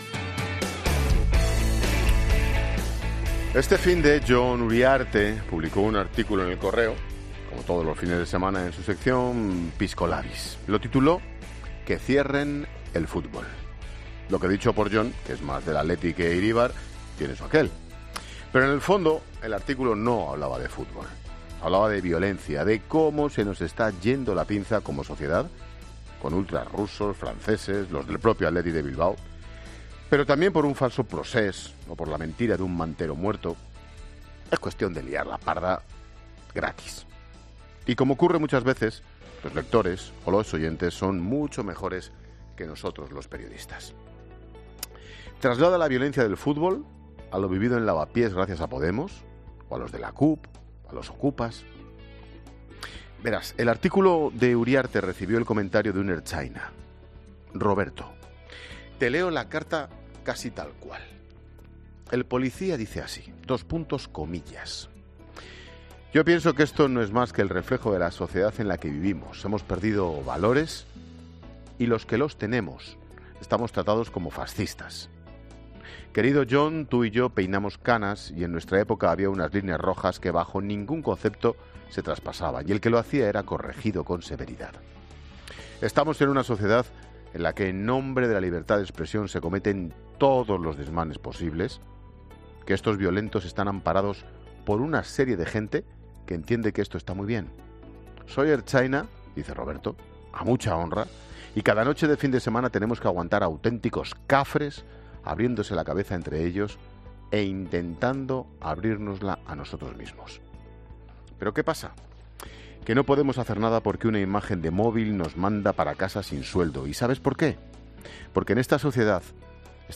Monólogo de Expósito
El comentario de Ángel Expósito sobre la violencia en el fútbol o por los altercados en el barrio madrileño de Lavapiés.